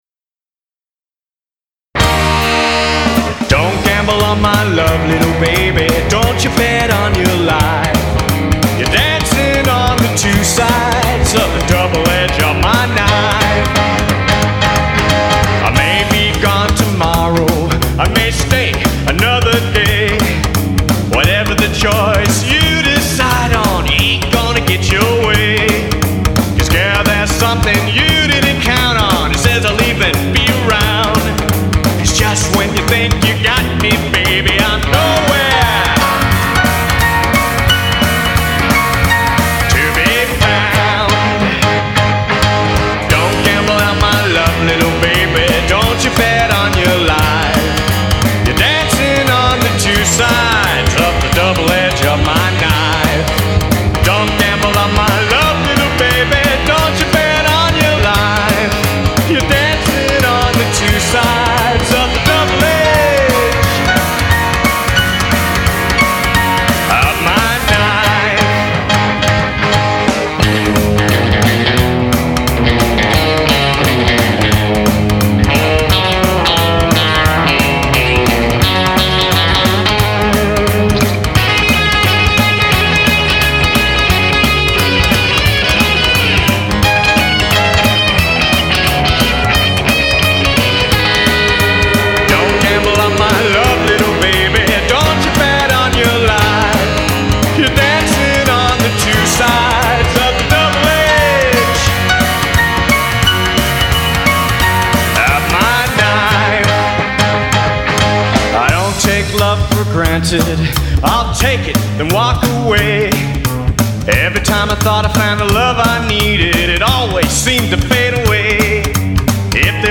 Vocals
Bass
Drums